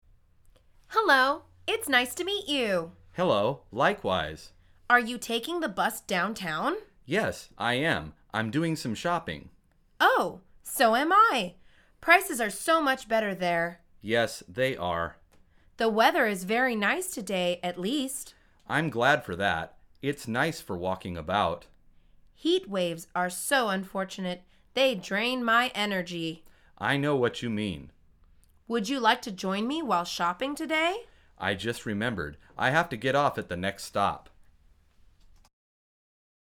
مجموعه مکالمات ساده و آسان انگلیسی – درس شماره بیست و یکم از فصل سوار شدن به اتوبوس: همسایه پرحرف